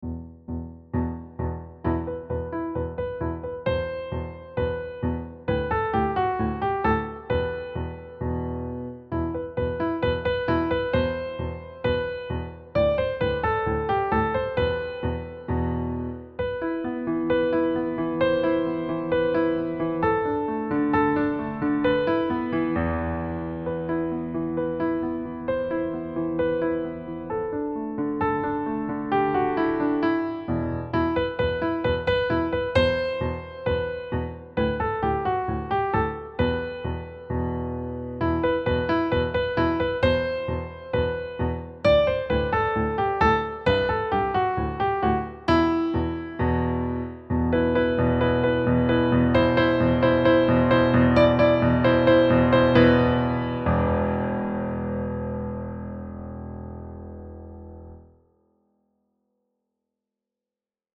dramatic flair and rhythmic excitement
Key: E Natural Minor, with position movements
Time Signature: 4/4